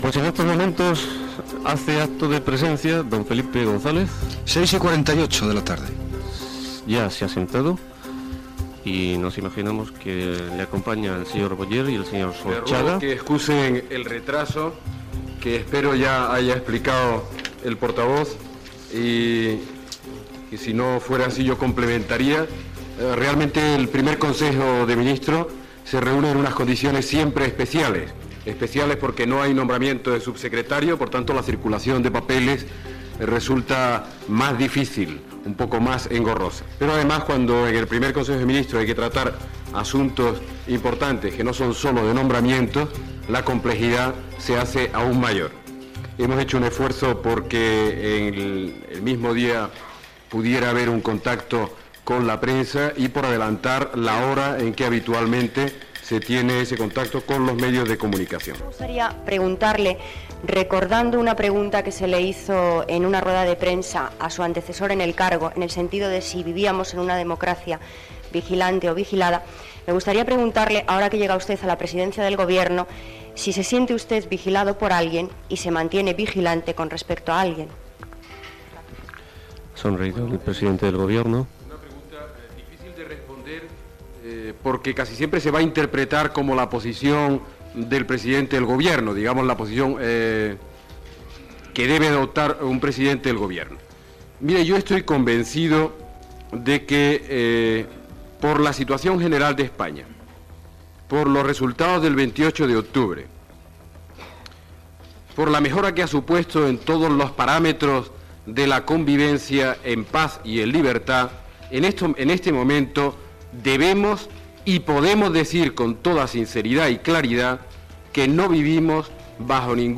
Transmissió des del Palacio de la Moncloa de la roda de premsa després del primer Consell de Ministres presidit per Felipe González.
Primera pregunta al president del Govern Felipe González.
Informatiu